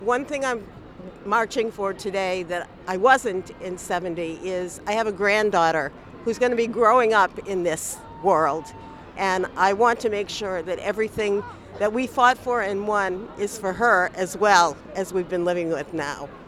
SPEAKS TO A WOMAN